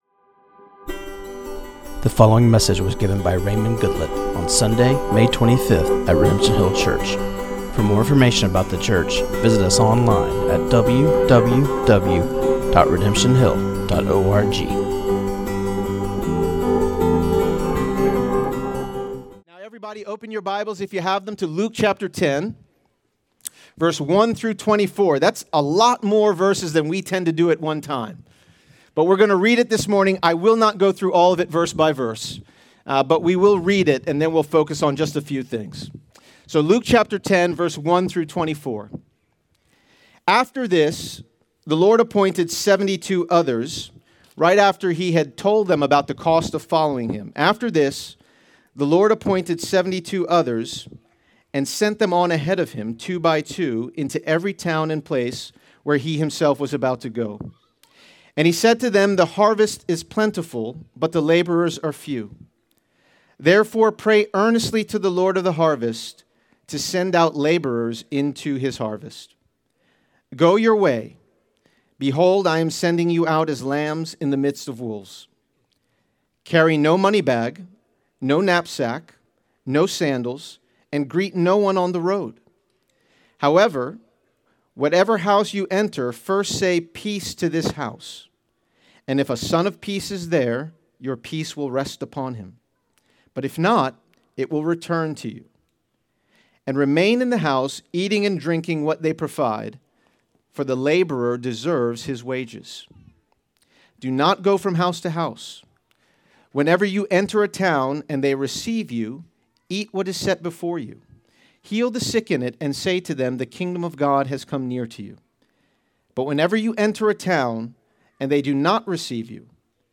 This sermon on Luke 10:1-24 was preached